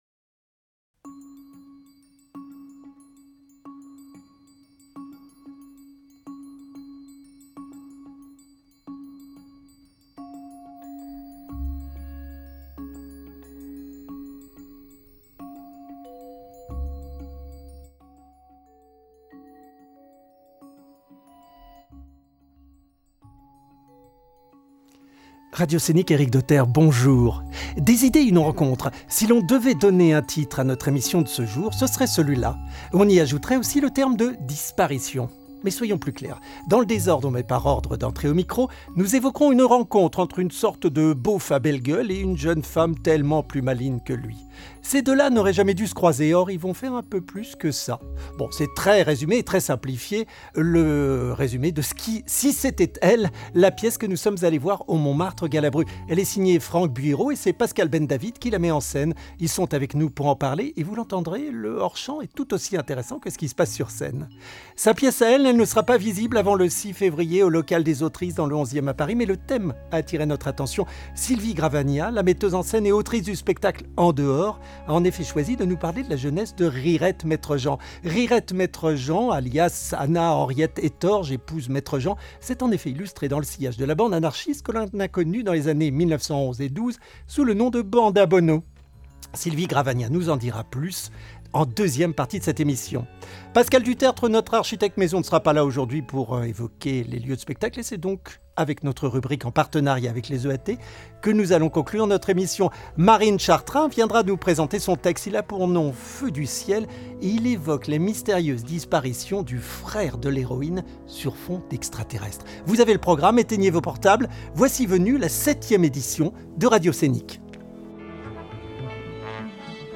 Nos éléments sonores et musicaux